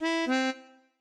melodica_ec.ogg